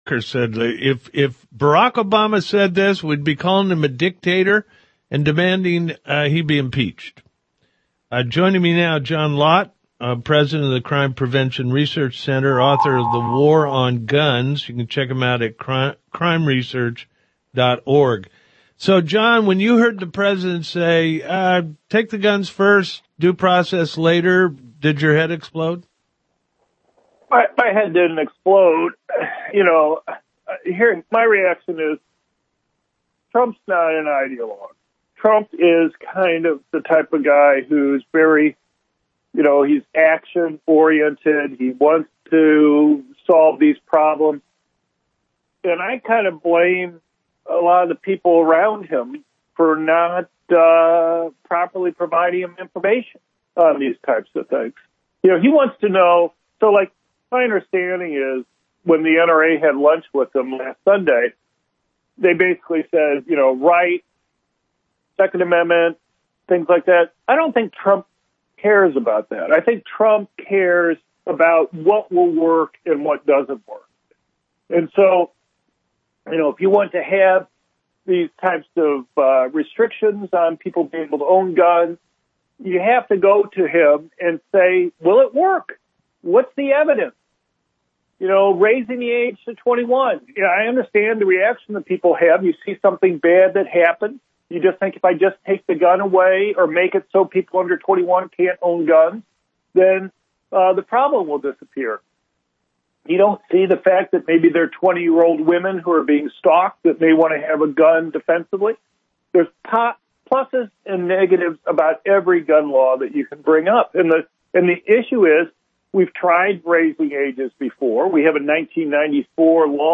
Dr. John Lott talked to John Gibson on his national radio show to talk about Trump’s comments during his meeting on Wednesday with Congress.